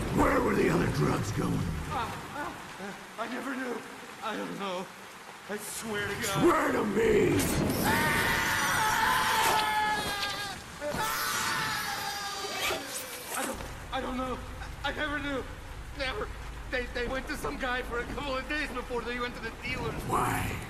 Audio recording of Christian Bale 's "Batman voice" as heard in Batman Begins . Batman interrogates a thug after capturing him.
Christian_Bale_Batman_voice.ogg.mp3